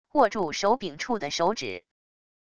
握住手柄处的手指wav音频